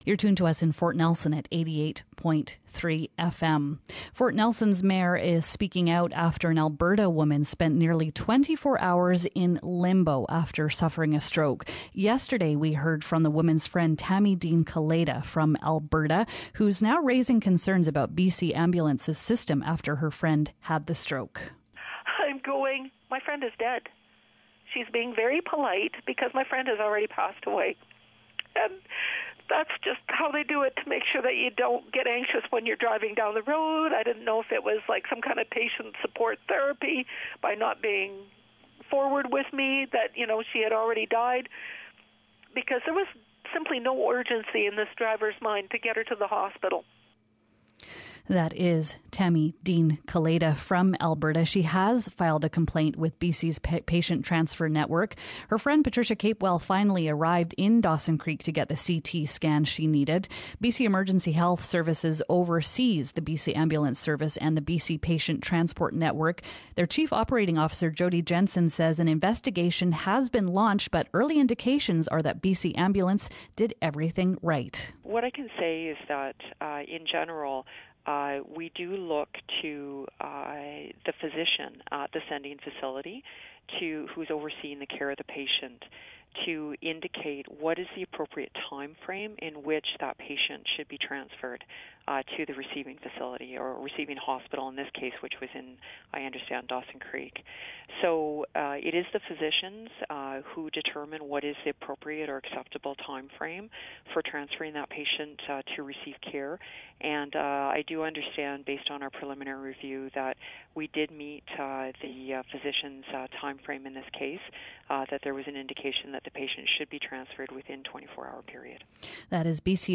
Fort Nelson's mayor, Bill Streeper, is speaking out after an Alberta woman spent nearly 24 hours in limbo after suffering a stroke. He joins us now for more on this story.